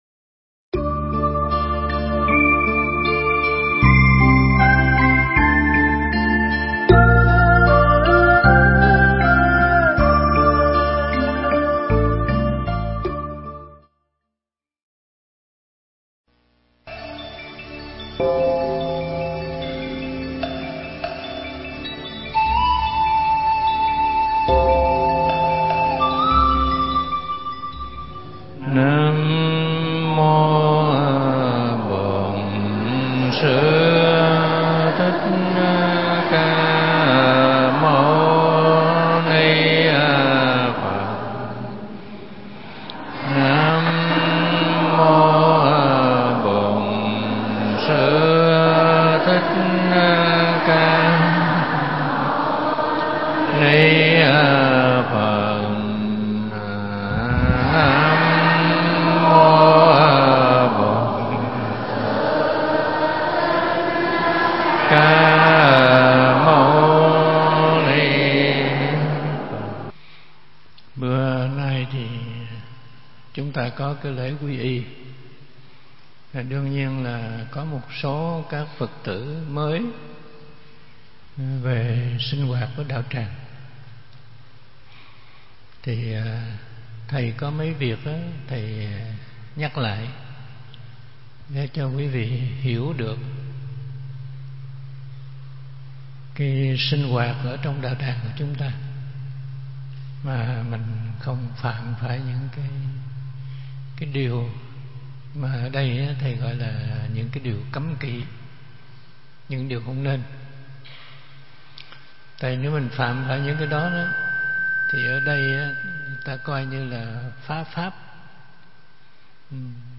Nghe Mp3 thuyết pháp Bổn Môn Pháp Hoa phần 21 – Thích Trí Quảng
Mp3 Pháp Thoại Bổn Môn Pháp Hoa phần 21 – Hòa Thượng Thích Trí Quảng giảng tại Chùa Huê Nghiêm, Quận 2, (ngày 3 tháng 4 năm Quý Tỵ), ngày 12 tháng 5 năm 2013